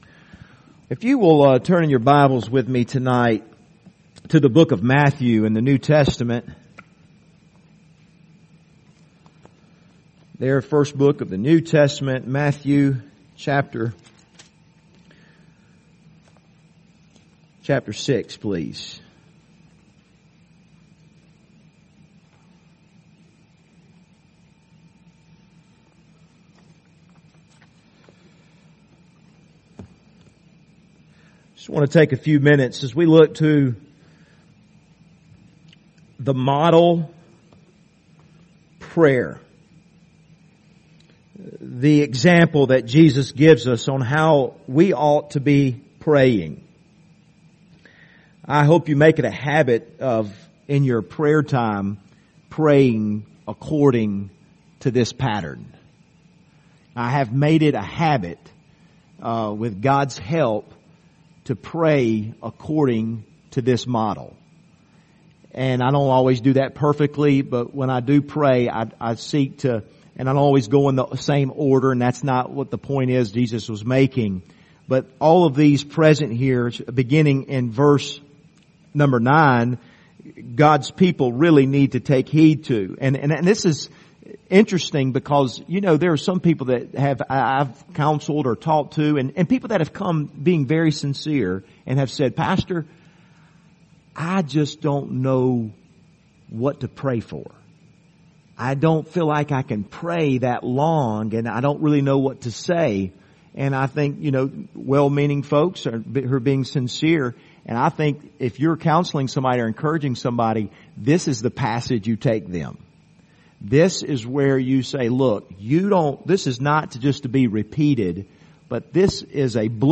Passage: Matthew 6:9-13 Service Type: Wednesday Evening